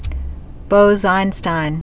(bōzīnstīn)